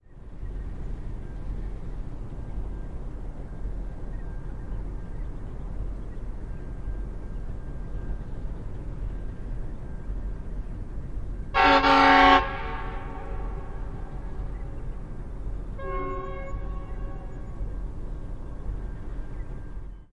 描述：Nene Valley铁路上Sutton Cross Halt的气温达到了32度的闷热。 31级31108号列车从彼得伯勒乘坐火车经过Halt，然后驶向Wansford。
Tag: 铁路-的英 机车 铁路 火车 柴油 现场录音 立体声 发动机